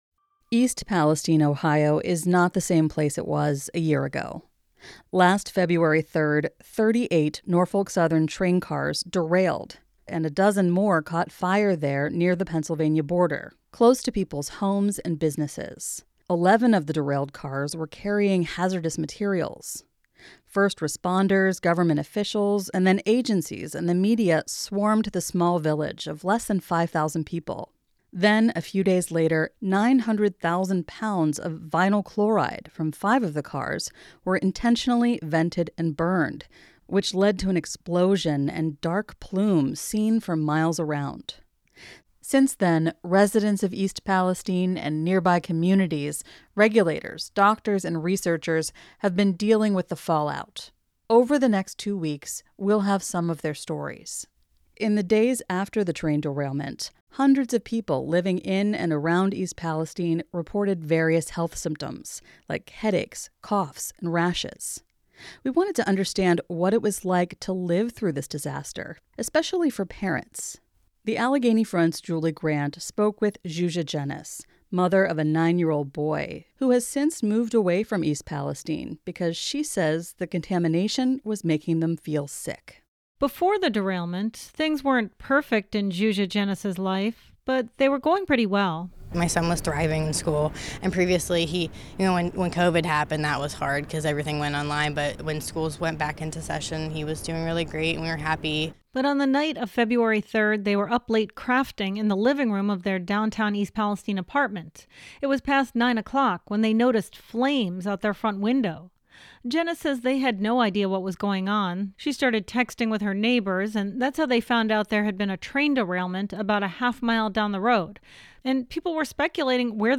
Reporters Roundtable: What we learned from our investigation in East Palestine